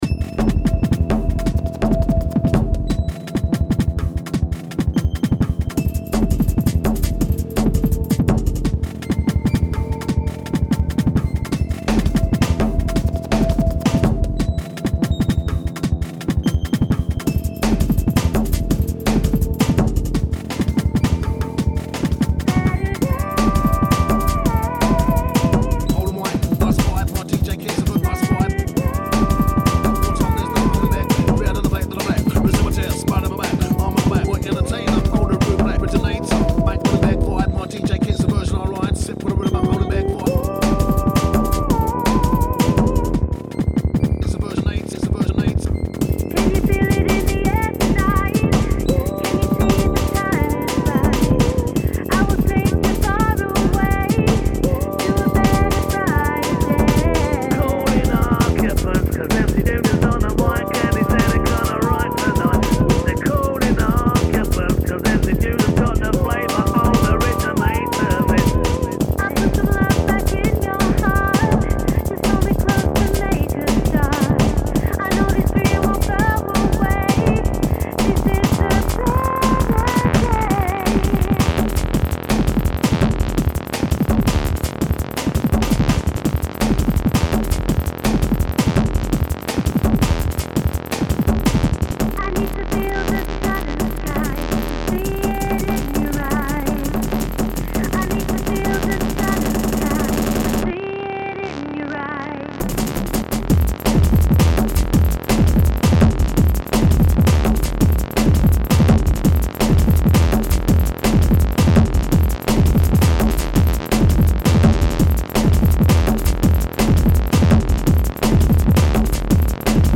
Drum'n'bass , Tekstep